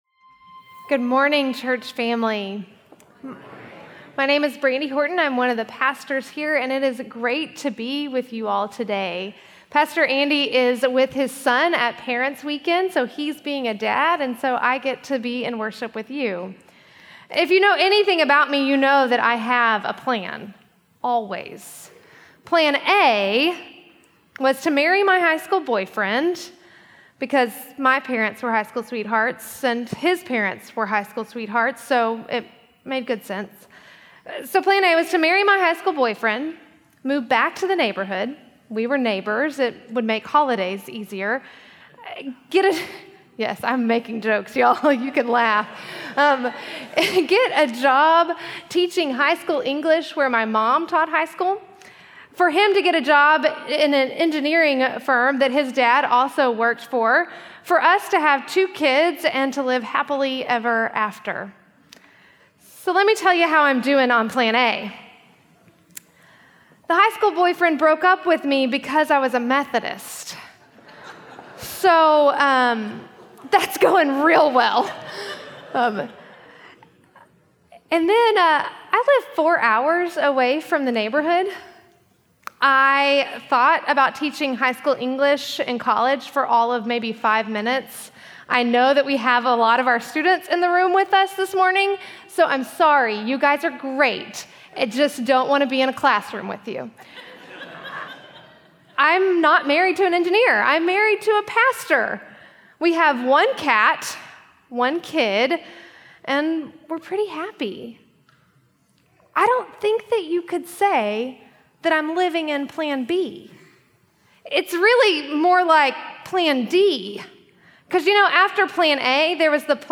Service Type: Traditional